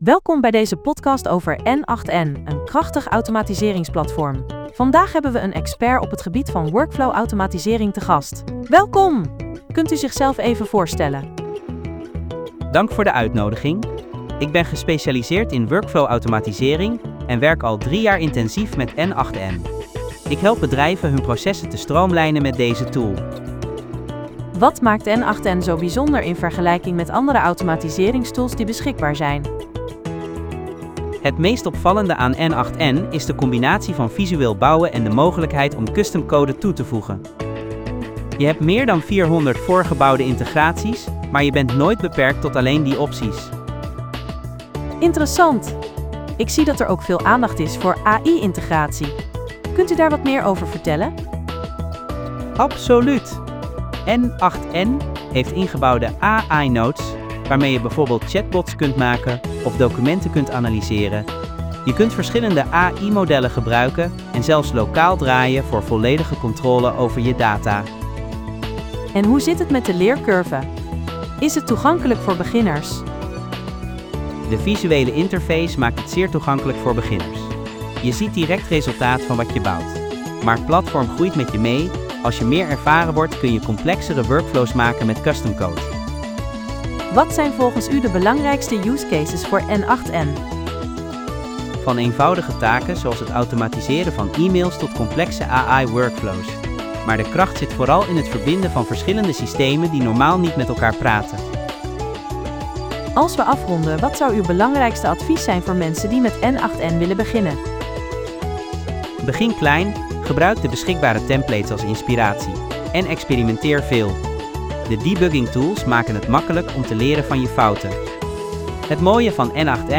Podcast gegenereerd van tekst content (4313 karakters)